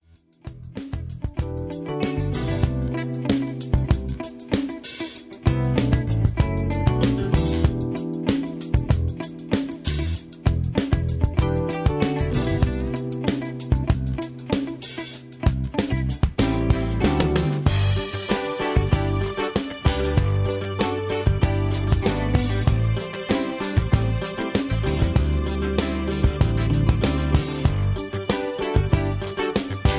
(Version-1, Key-G) Karaoke MP3 Backing Tracks
Just Plain & Simply "GREAT MUSIC" (No Lyrics).